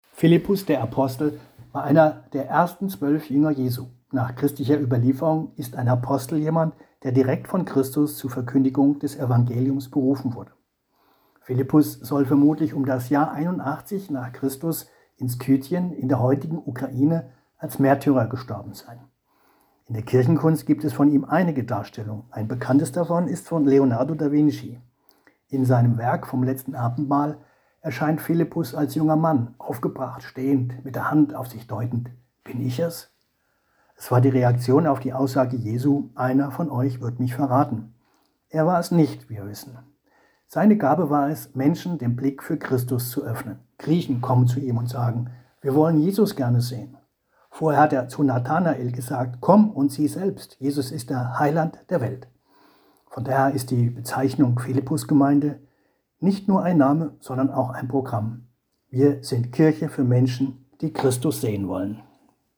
Das Große Geläut der Glocken der Johanneskirche Gießen
Großes Geläut